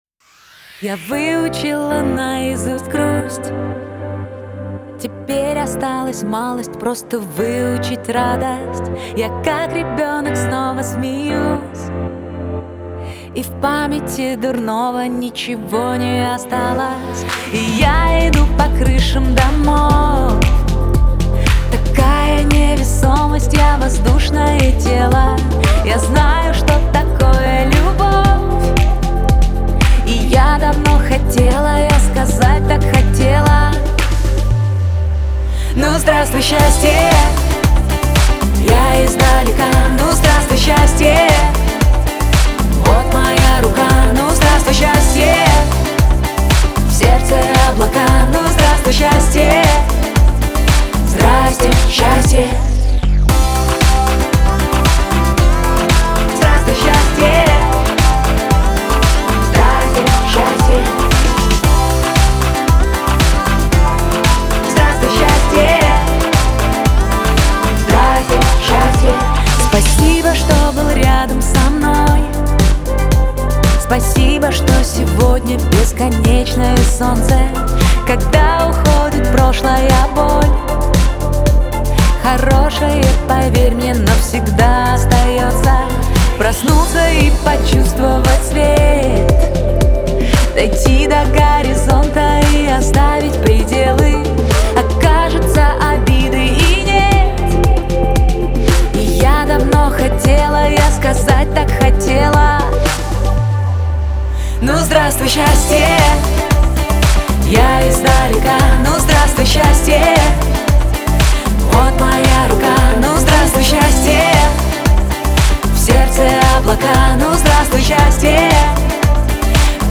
это яркая и вдохновляющая песня в жанре поп